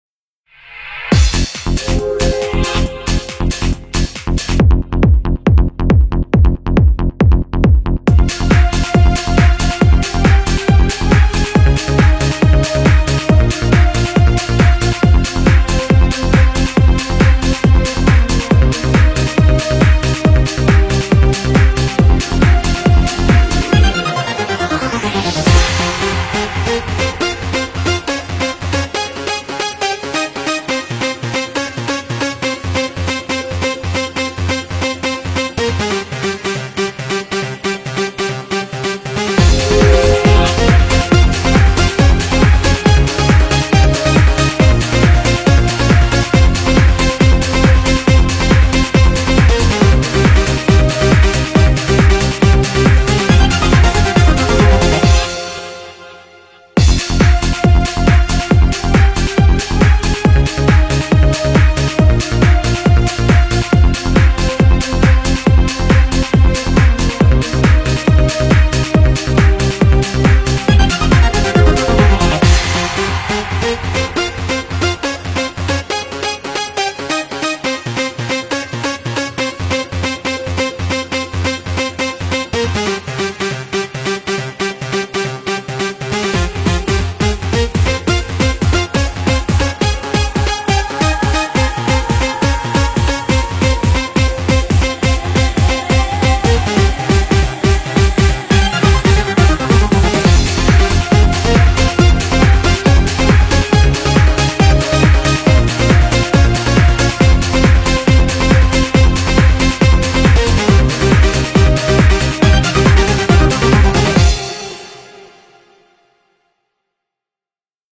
BPM138
Audio QualityPerfect (High Quality)
cemented its status as a futuristic and emotional anthem.